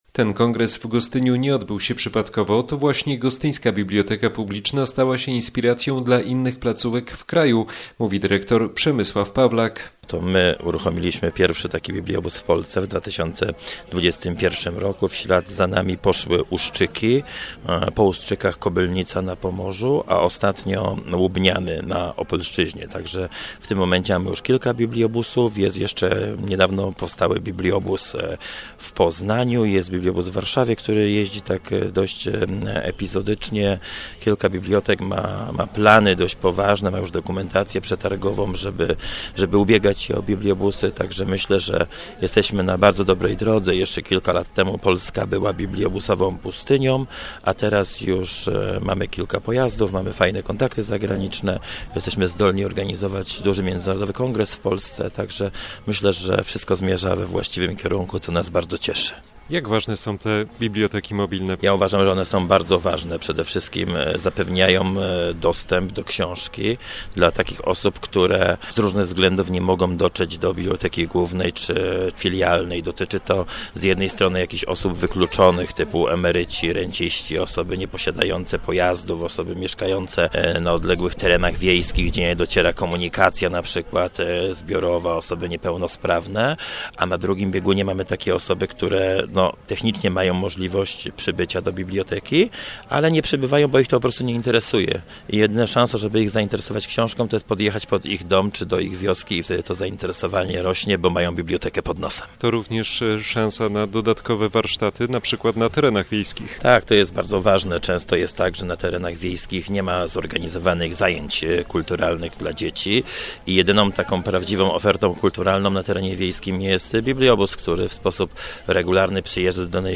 Dodajmy, że na kongresie pojawili się przedstawiciele mobilnych bibliotek także ze Słowenii, Chorwacji, Niemiec, Łotwy, Portugalii i Ukrainy.